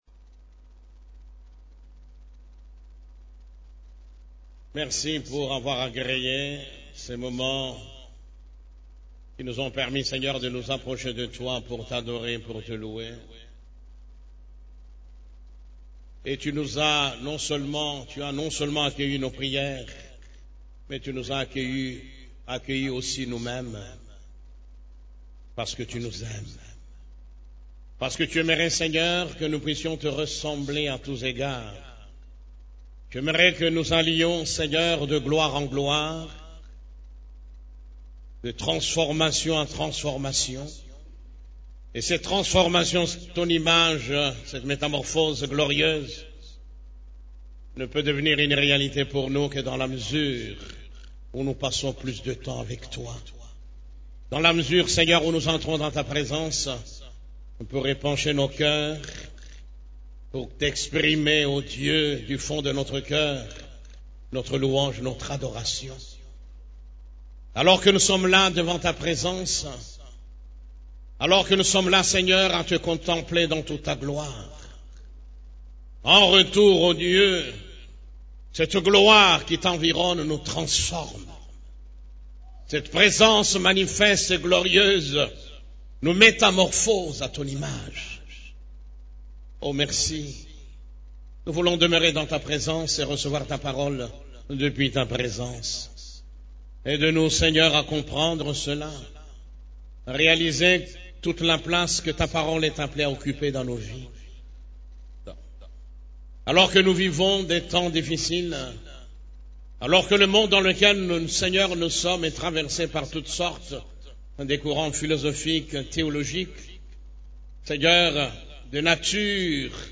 CEF la Borne, Culte du Dimanche, Le type de disciples que Jésus choisit et utilise